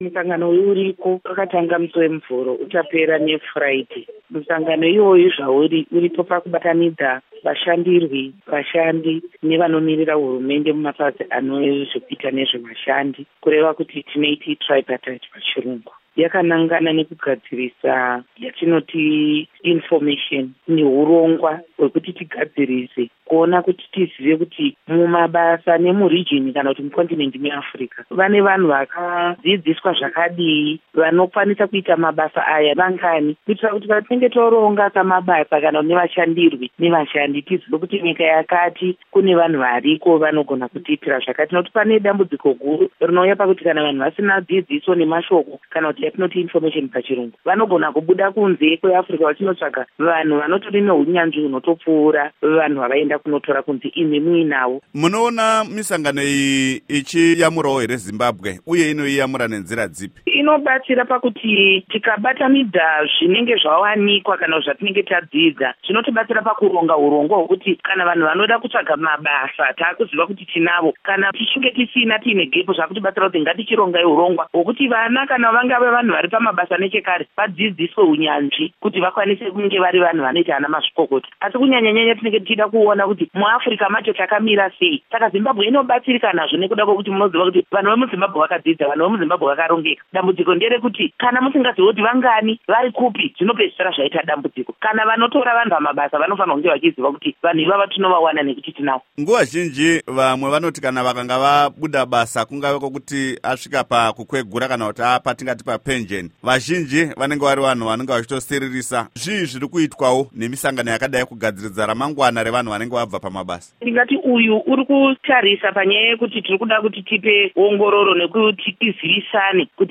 Hurukuro naAmai Paurina Mpariwa Gwanyanya